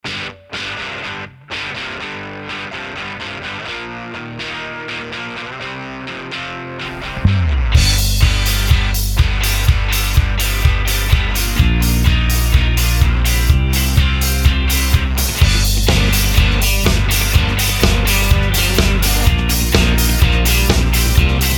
rocková skupina
na něj nahrála čtrnáct anglicky zpívaných písniček.